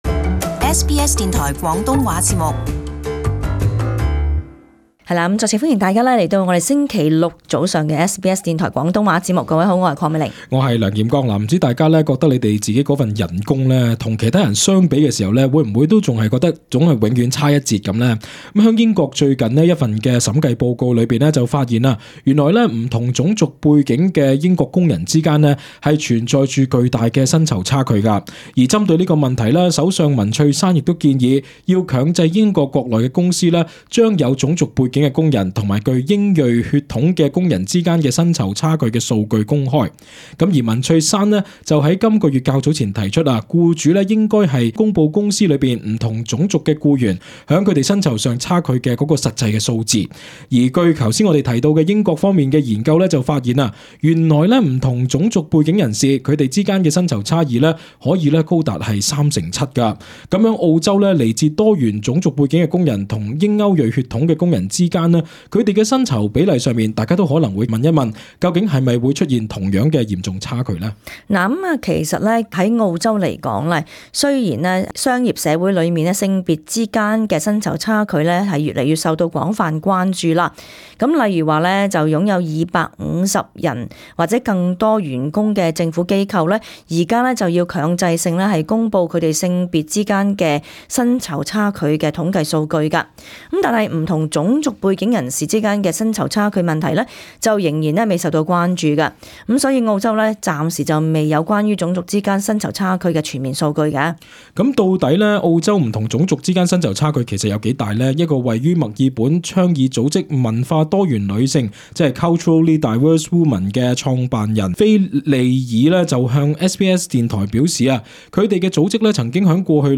【時事報導】澳洲不同種族的的薪酬差異嚴重嗎？